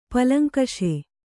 ♪ palaŋkaṣe